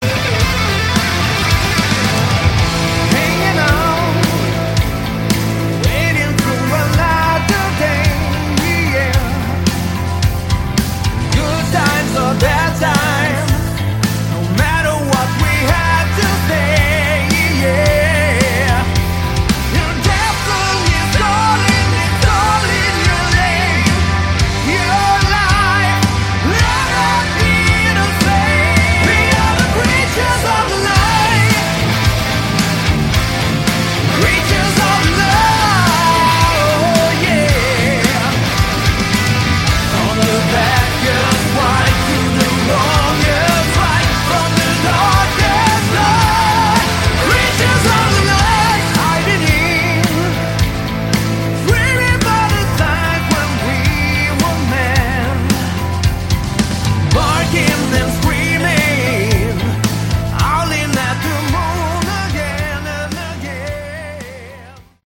Category: Melodic Rock
lead vocals
keyboards
lead guitars, backing vocals
drums
bass